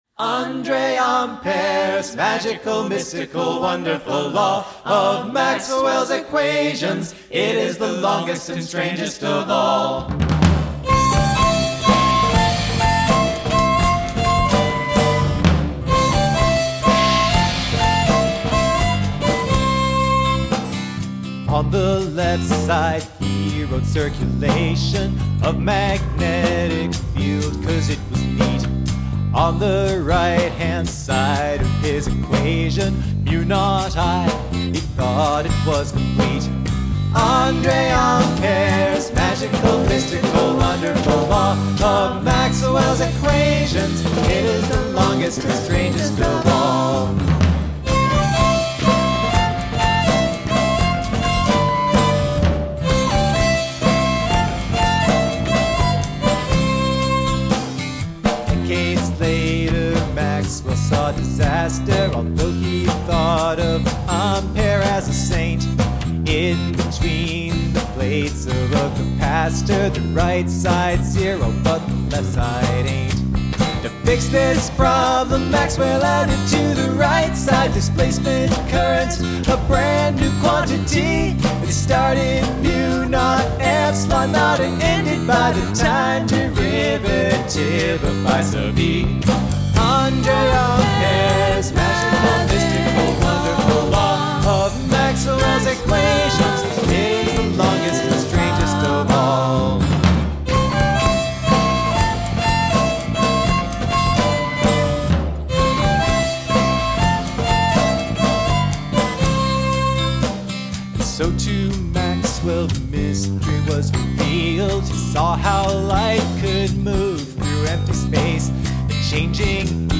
folk-rock band